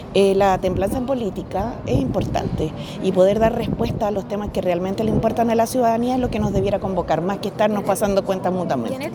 En medio de este escenario, la excandidata presidencial y exministra del Trabajo, Jeannette Jara, llamó a abordar el quiebre con moderación, privilegiando el diálogo y evitando cerrar los espacios de conversación.